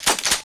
WEAP CLI01.wav